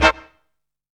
HOLDIN HIT.wav